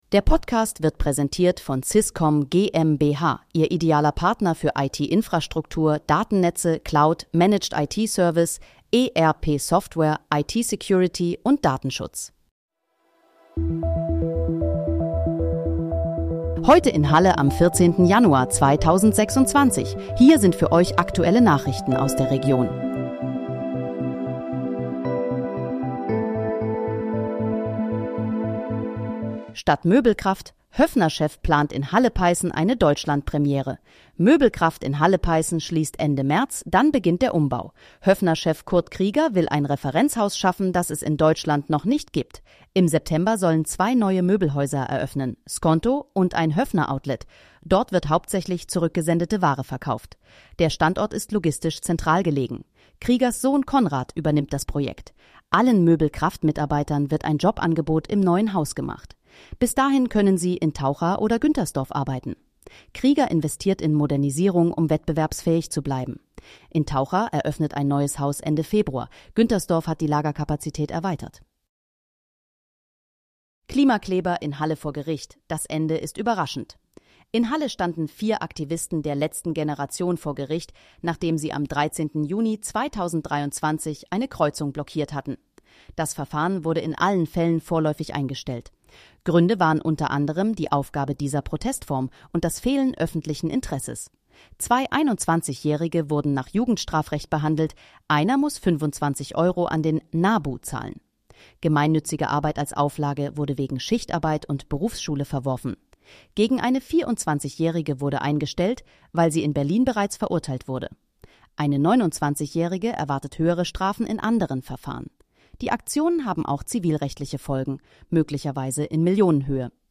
Heute in, Halle: Aktuelle Nachrichten vom 14.01.2026, erstellt mit KI-Unterstützung
Nachrichten